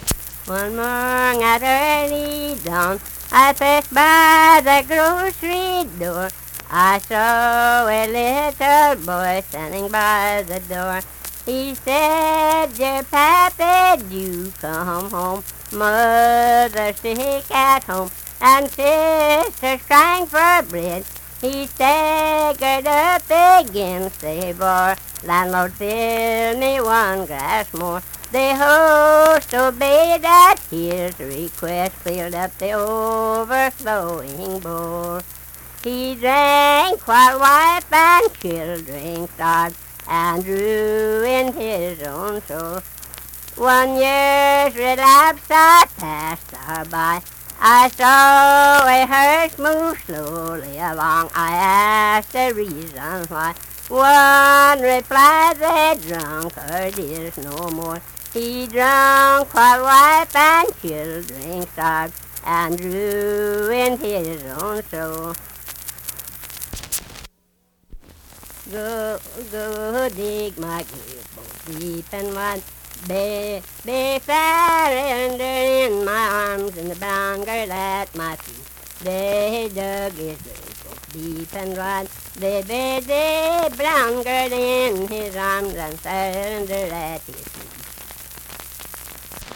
Unaccompanied vocal music
Voice (sung)
Logan County (W. Va.), Lundale (W. Va.)